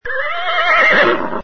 Descarga de Sonidos mp3 Gratis: caballo 15.
CaballoHORSEjiaa.mp3